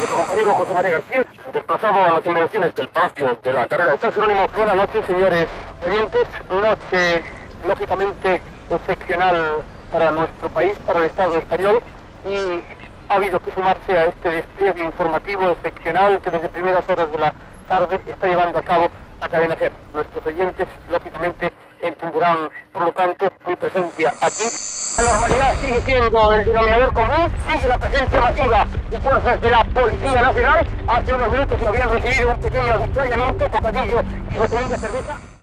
Connexió amb la unitat mòbil de la Cadena SER al Palacio del Congreso de los Diputados de la carretera de San Jerónimo de Madrid.
Informatiu